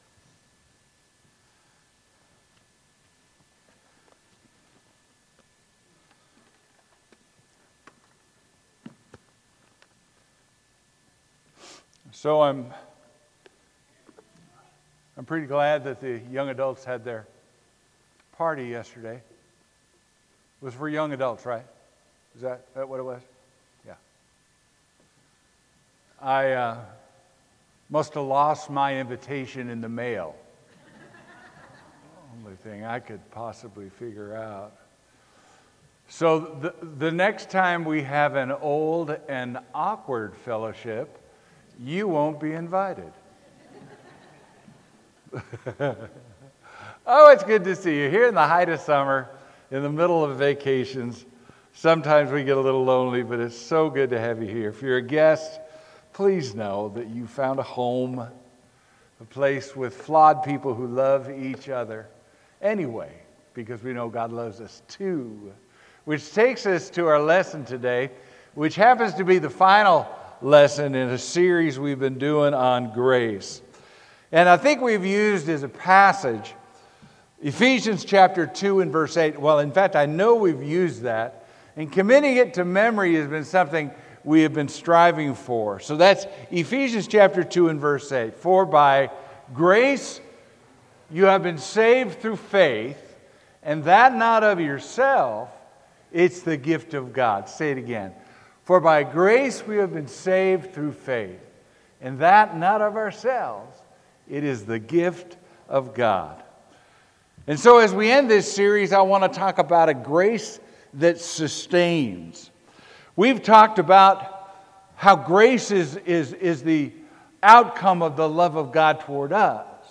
Sermon: The Grace That Sustains